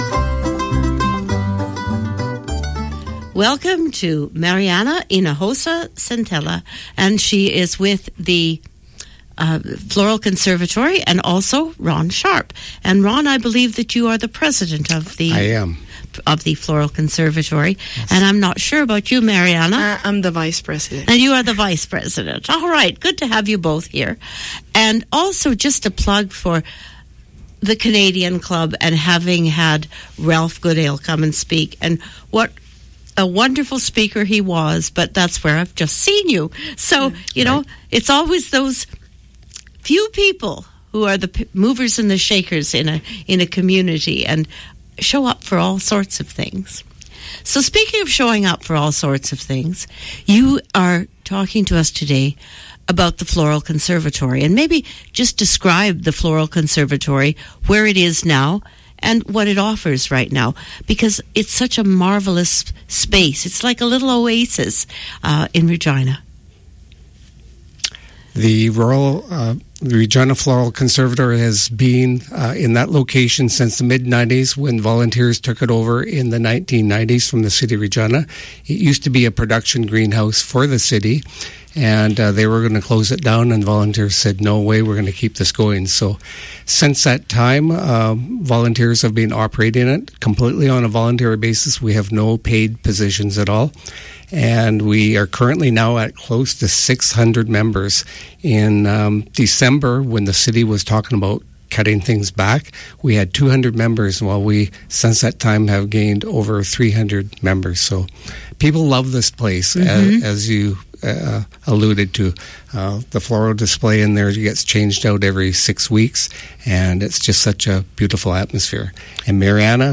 The interview is about 25 minutes long and you can listen to it below.